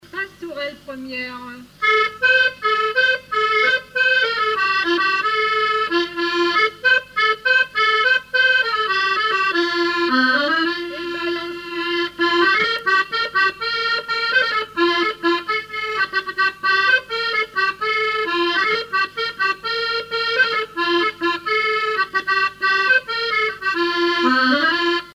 Mémoires et Patrimoines vivants - RaddO est une base de données d'archives iconographiques et sonores.
danse : quadrille : pastourelle
Musique du quadrille local
Pièce musicale inédite